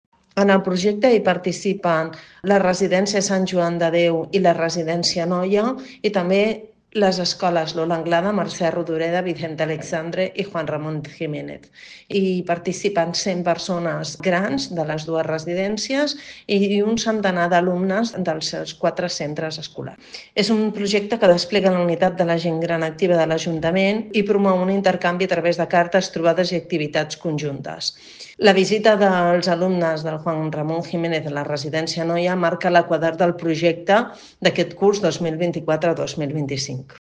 Montserrat Salas, regidora de Gent Gran de l'Ajuntament de Martorell